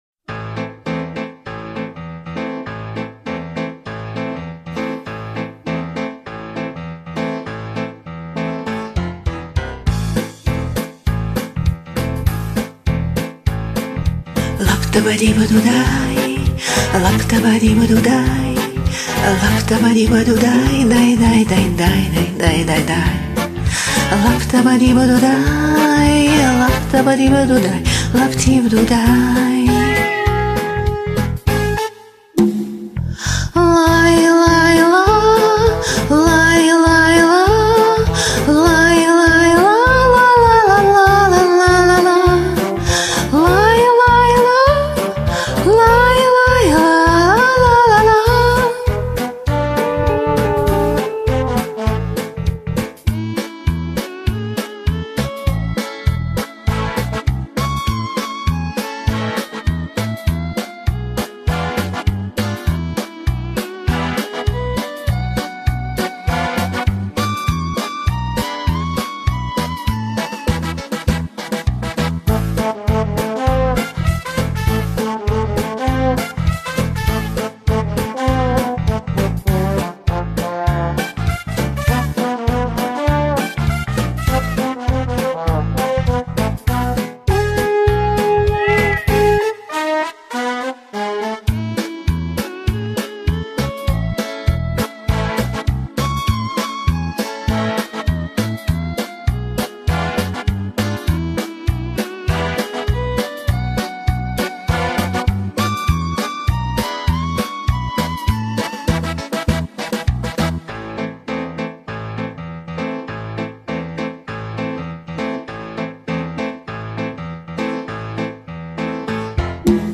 с душой и голос нежный flower